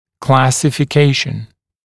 [ˌklæsɪfɪ’keɪʃn][ˌклэсифи’кейшн]классификация, классифицирование